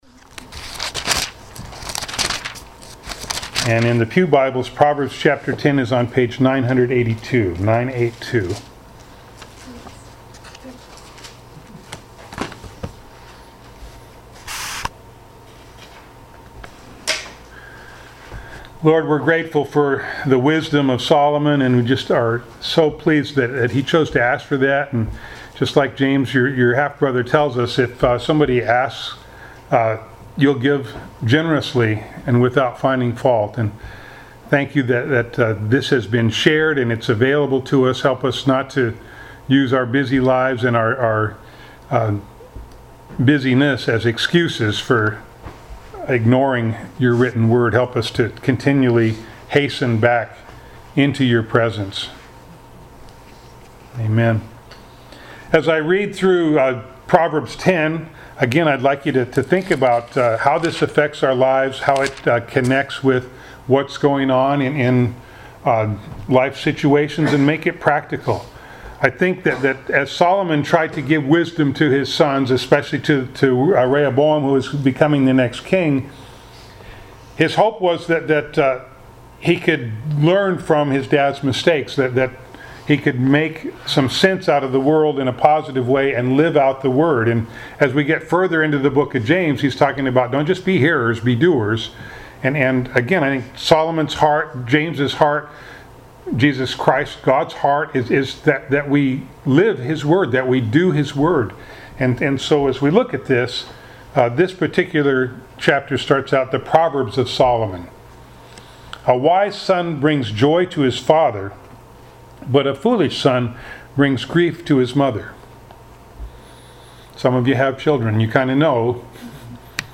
Passage: James 1:21 Service Type: Sunday Morning